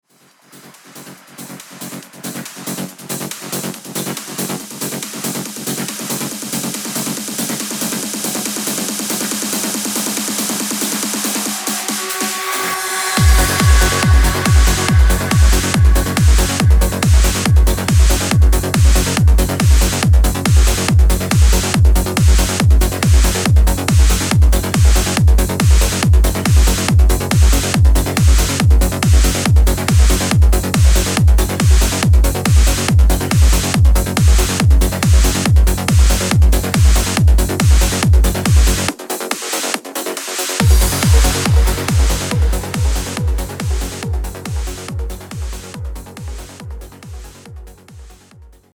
Eurodance 90x. Как накрутить такое арпеджио\трещётку?
Вашу, трещетку я сделал в сайленте, используя 3 осциллятора: пила+пила+шум Без...